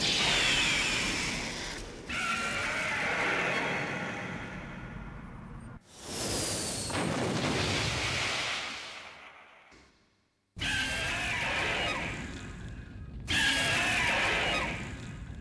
Zoiger_Roars.ogg